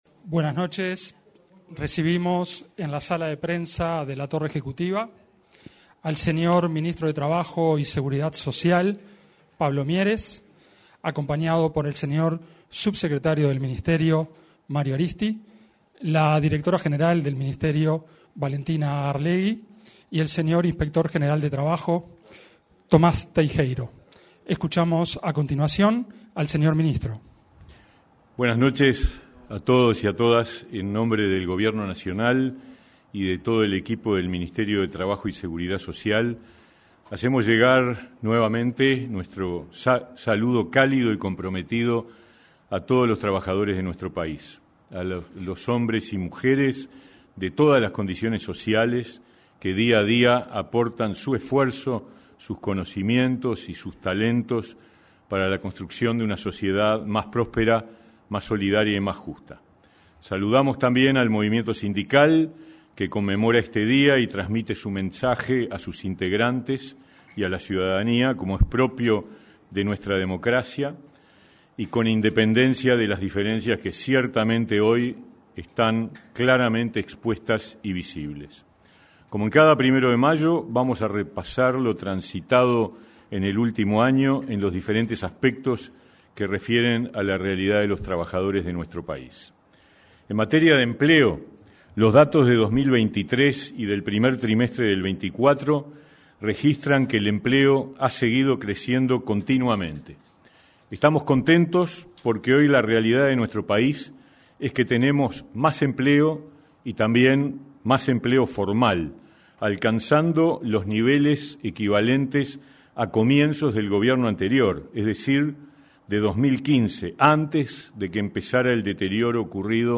Conferencia de prensa del ministro de Trabajo y Seguridad Social
Conferencia de prensa del ministro de Trabajo y Seguridad Social 01/05/2024 Compartir Facebook Twitter Copiar enlace WhatsApp LinkedIn Este 1.º de mayo, el ministro de Trabajo y Seguridad Social, Pablo Mieres, se expresó en una conferencia de prensa con motivo de una nueva conmemoración del Día de los Trabajadores.